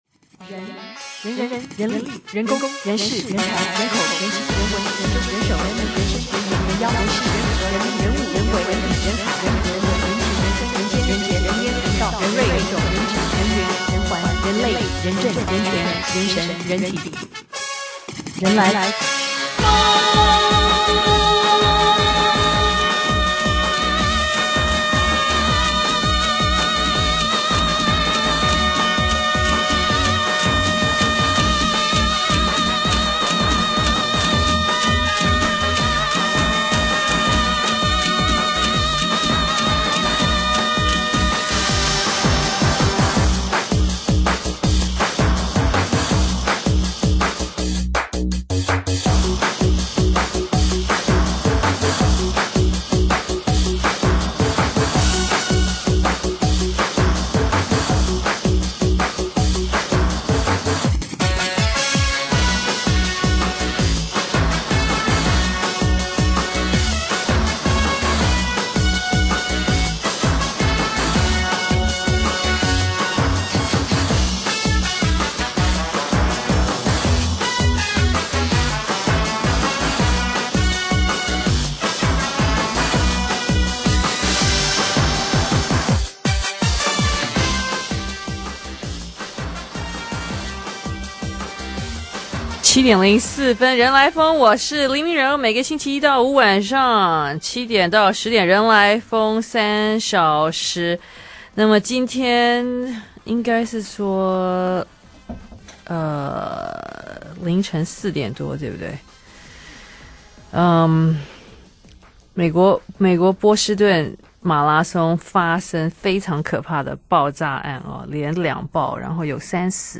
專訪
卡奴電訪。